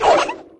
girl_toss_plane.wav